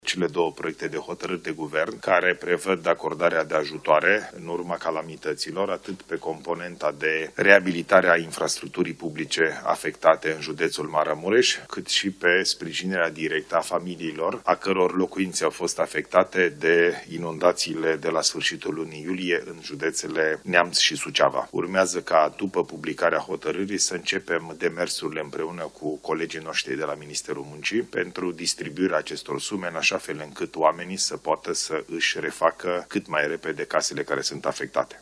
Ministerul Muncii va începe distribuirea sumelor pentru refacerea locuințelor după publicarea hotărârii, a anunțat premierul Ilie Bolojan